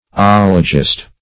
Oligist \Ol"i*gist\, n. [See Oligist, a.]